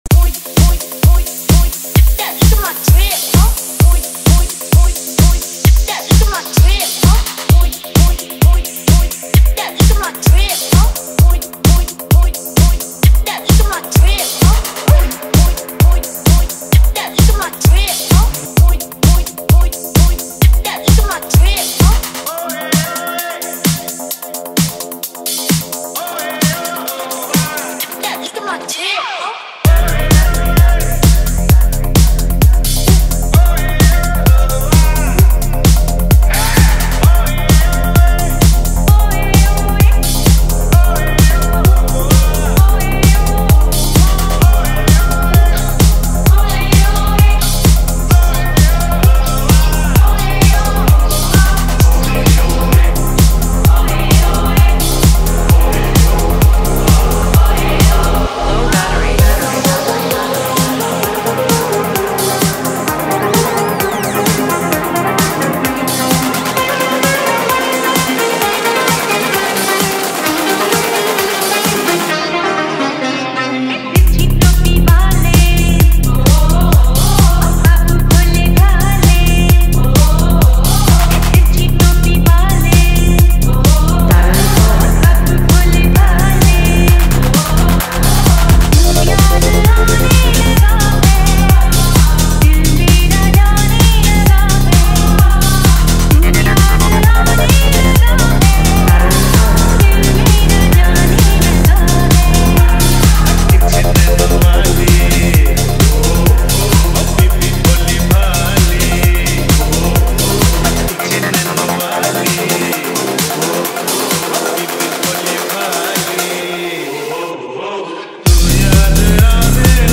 • Genre: Bollywood Mashup / DJ Remix
• Smooth and professional mixing techniques
• Balanced bass and clear vocal output
• High-energy beat drops suitable for dance environments
• Consistent tempo for uninterrupted listening